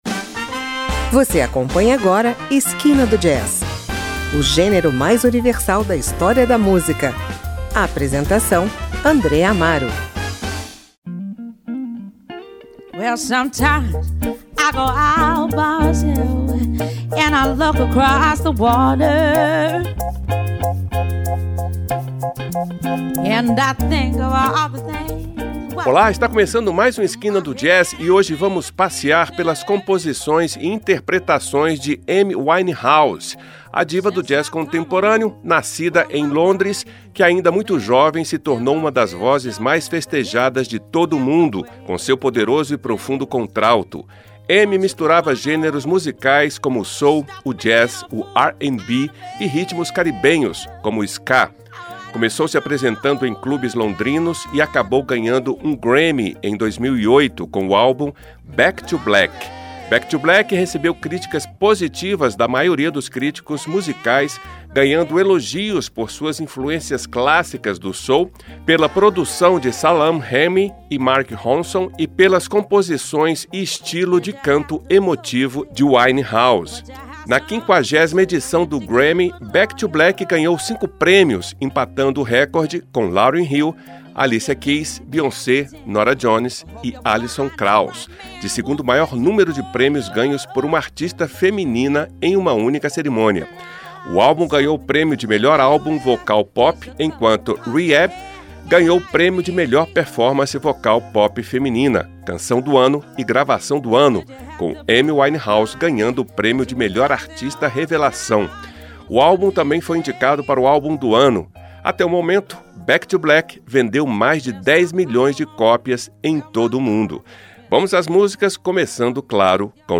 jazz contemporâneo